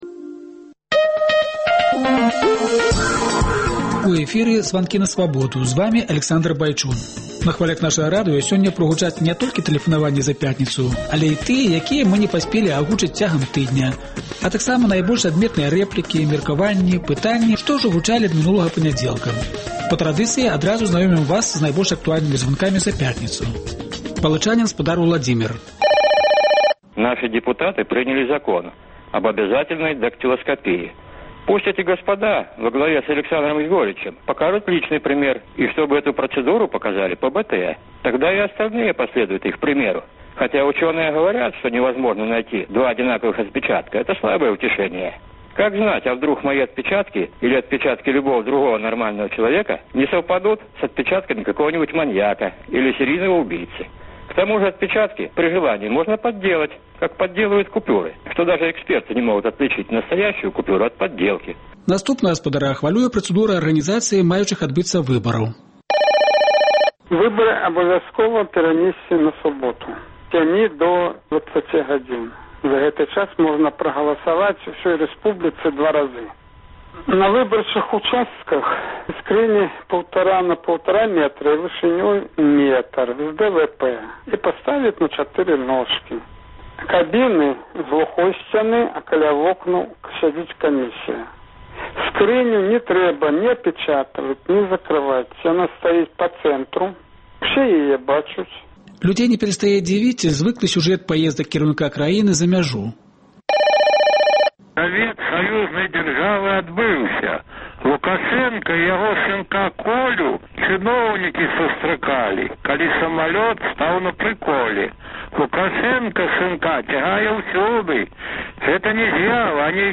Тыднёвы агляд званкоў ад слухачоў Свабоды